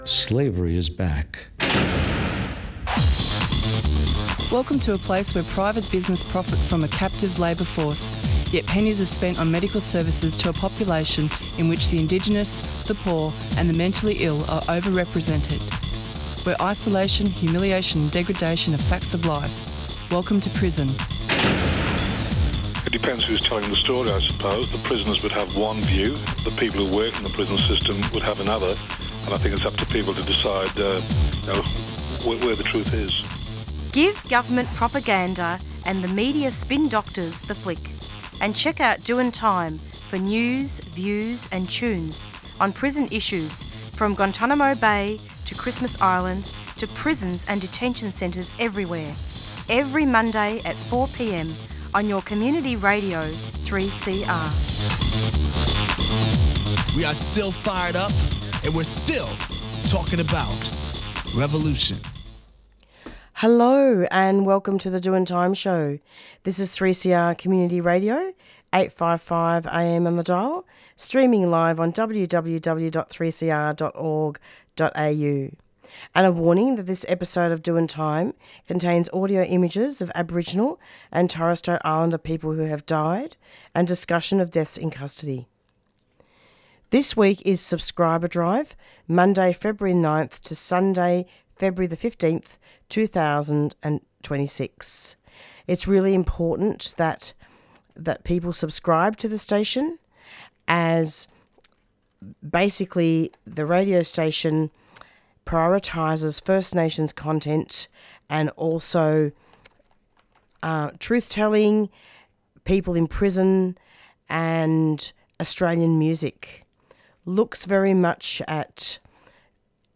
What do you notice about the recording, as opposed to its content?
This episode of Doin Time contains audio images of Aboriginal and Torres Strait Island Peoples who have died as well as mention of deaths in custody.